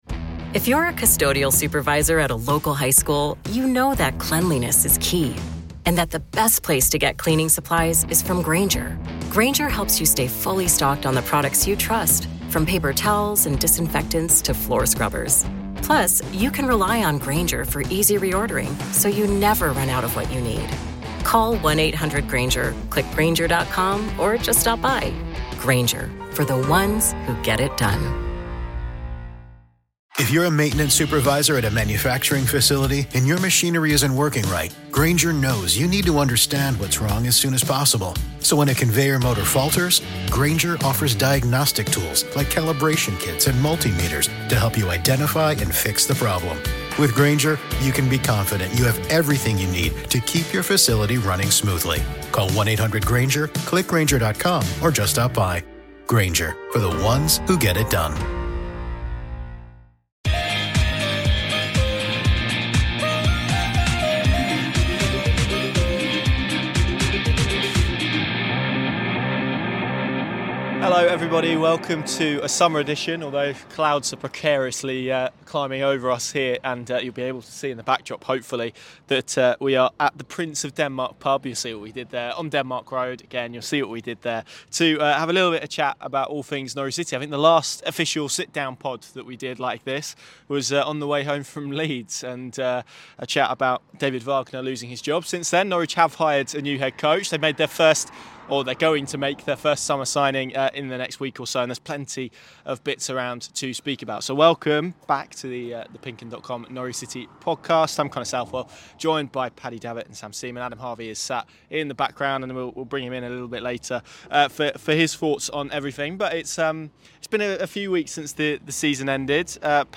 took a trip to the Prince of Denmark pub in Norwich to assess the summer thus far and what's ahead before pre-season begins in July.